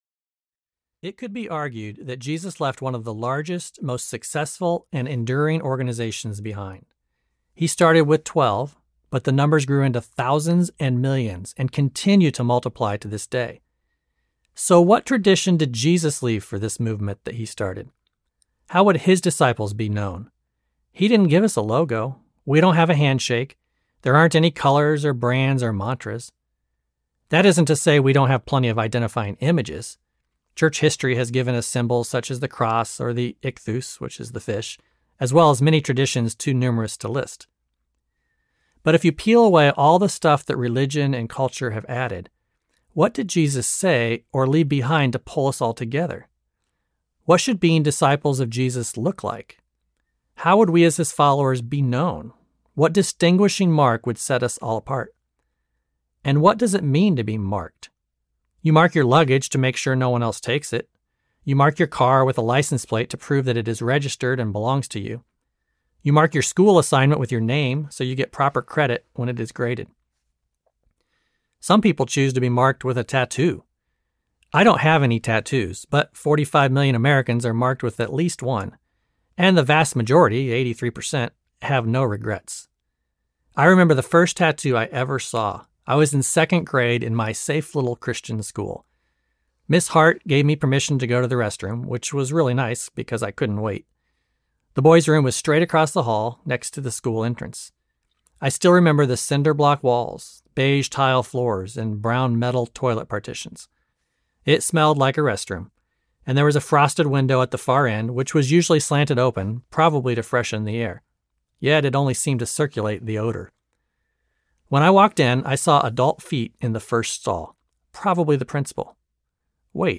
Marked by Love Audiobook
Narrator